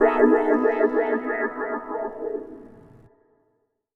SouthSide Trap Transition (16).wav